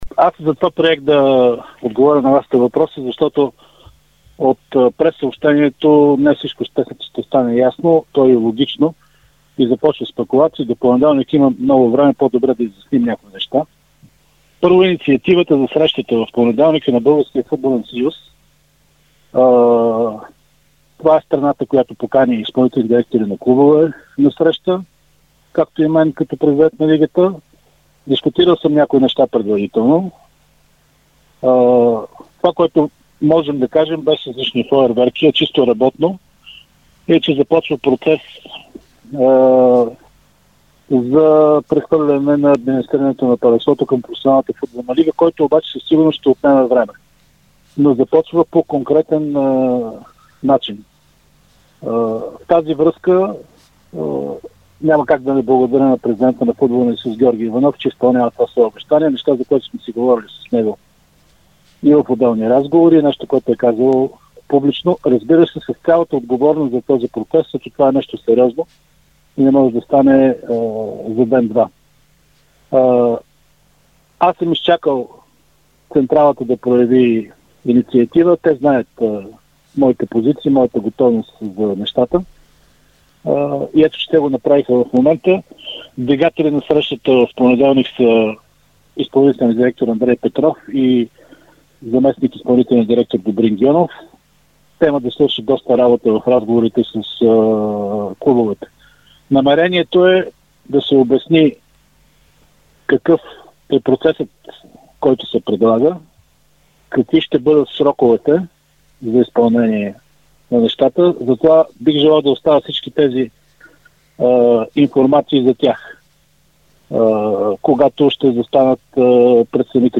Президентът на Българската професионална футболна лига – Атанас Караиванов, даде интервю пред Дарик радио и dsport, в което коментира темата около проекта за отделяне на Българската професионална футболна лига (БПФЛ) от БФС. Той заяви, че това може да се случи още през новия сезон, като подчерта, че няма да се появи „БФС-2“, а това ще бъде административно тяло с активно действие.